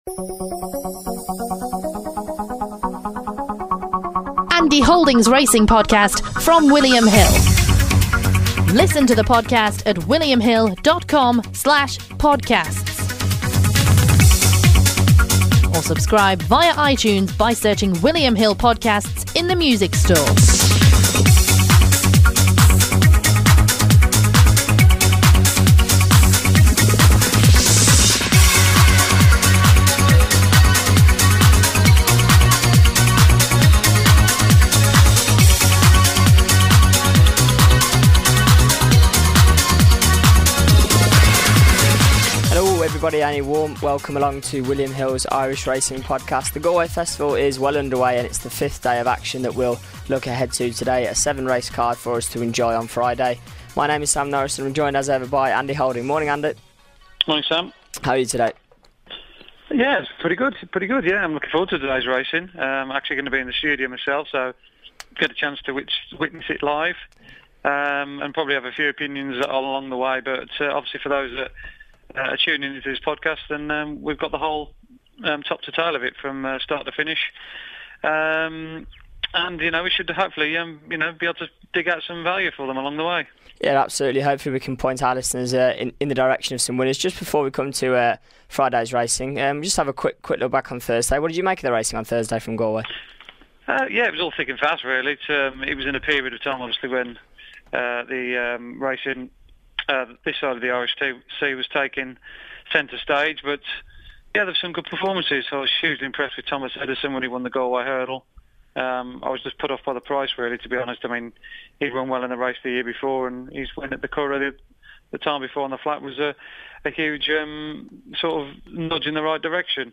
joined on the line